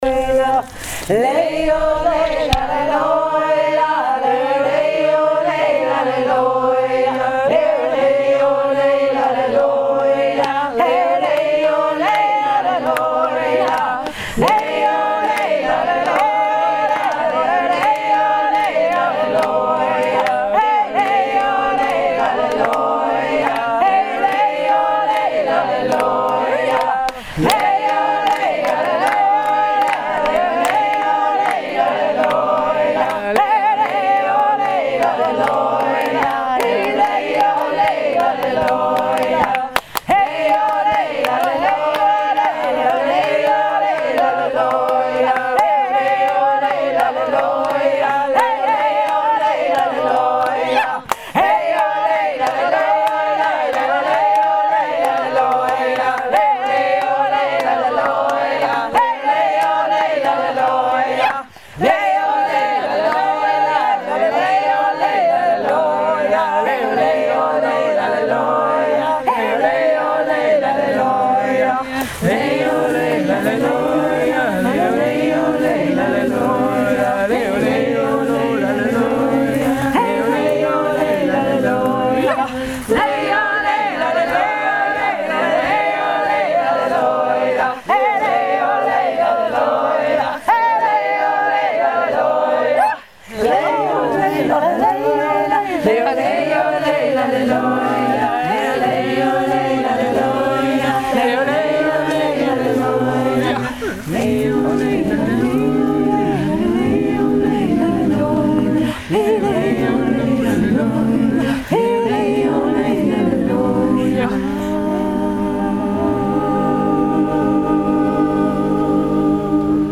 Jodeln im Einklang mit der Natur am Grundlsee 2016
So jodelten wir unsere Jodler unterm Blätterdach im "Grundseer Kurpark":